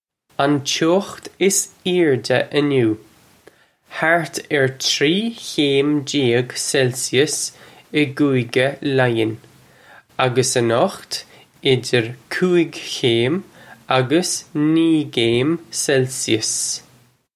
Pronunciation for how to say
Un choe-kht iss irr-juh in-new: hart urr chree khaym jay-ig celsius ih Goo-igga Lion, uggus anukht idger koo-ig khaym uggus nee gaym celsius.